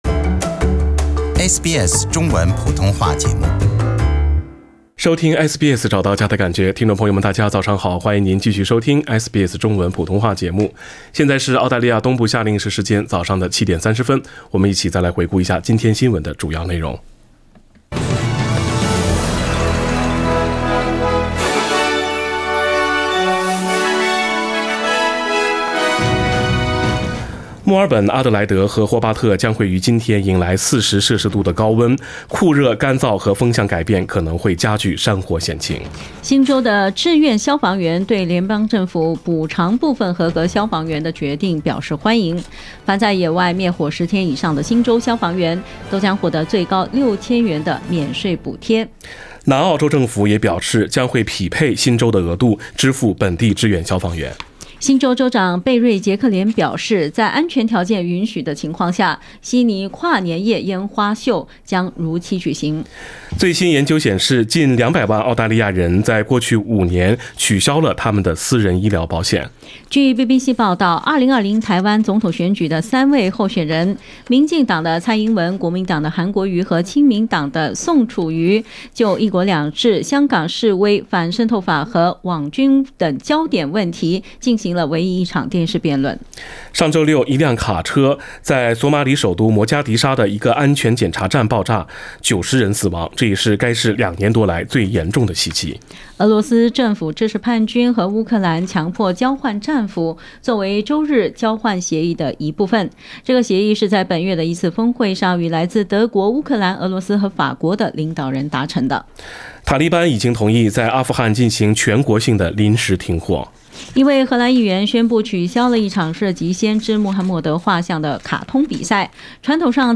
sbs早新闻(12月30日)